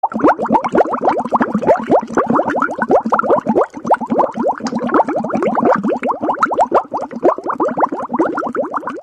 Boiling Water klingelton kostenlos
Kategorien: Soundeffekte
boiling-water-sound.mp3